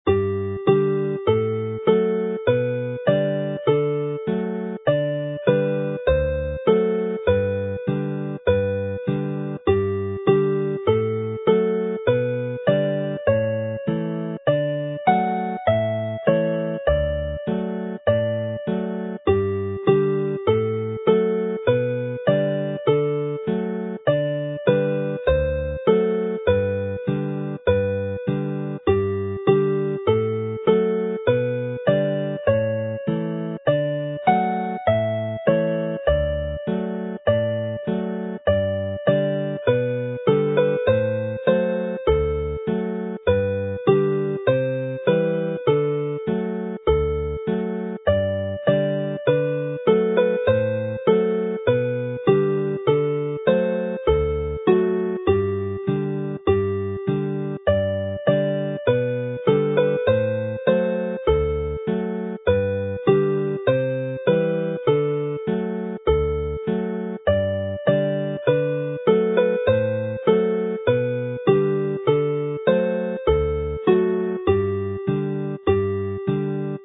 araf / slow
Hen alaw ddawns draddodiadol yw'r Cwac Cymreig.